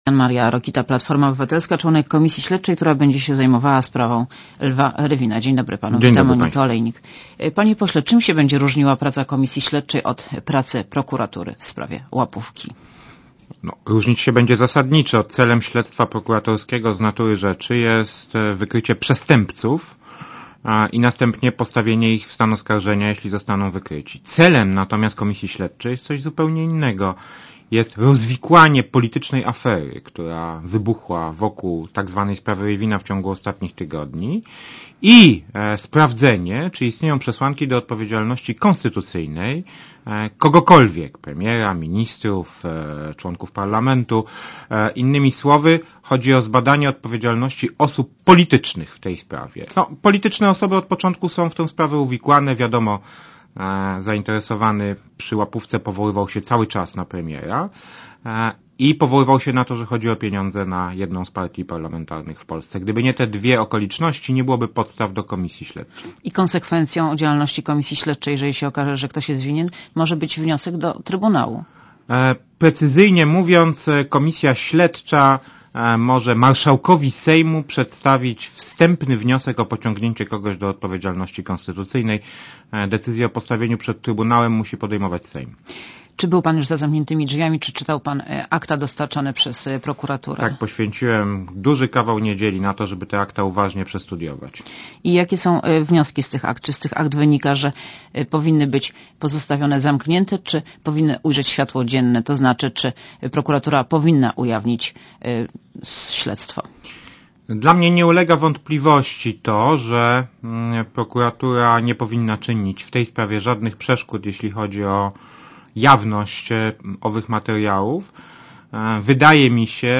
Monika Olejnik rozmawia z Janem Maria Rokitą z Paltformy Obywatelskiej